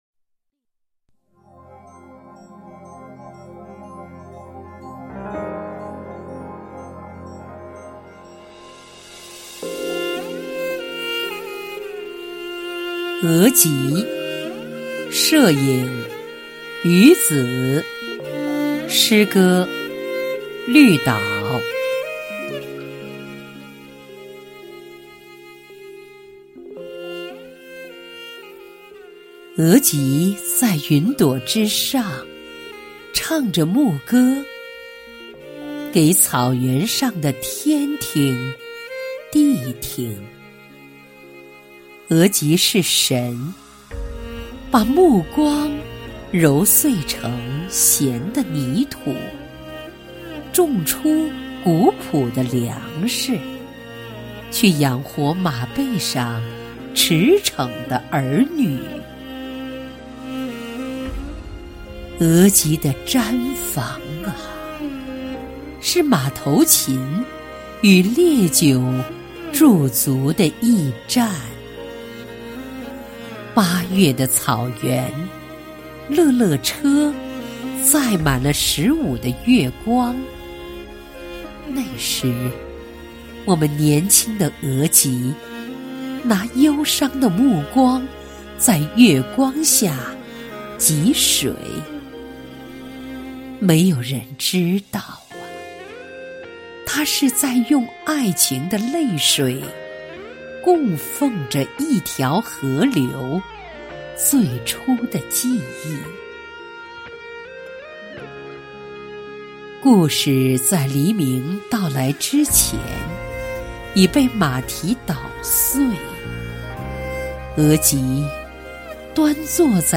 在悠扬舒缓的乐声中，她用深情圆润的声音朗诵着《献给母亲的圣章》。